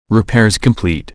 RepairComplete.wav